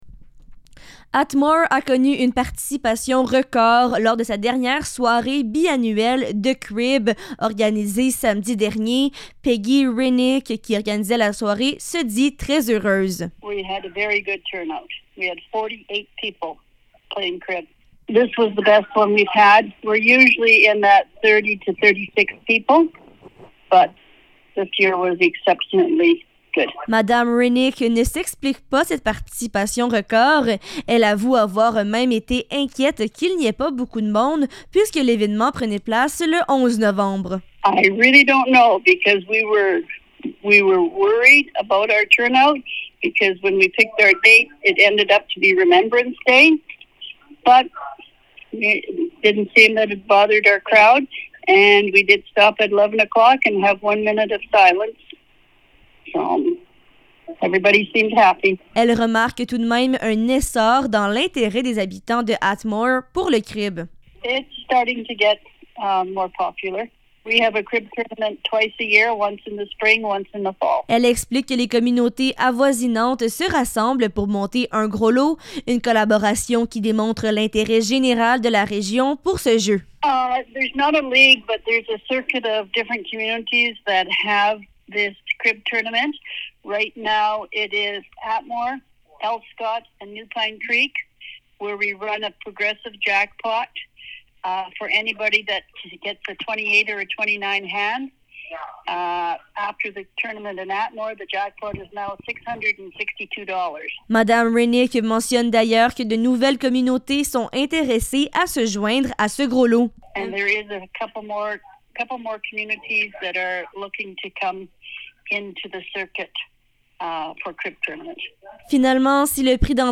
Un reportage